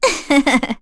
Erze-Vox-Laugh.wav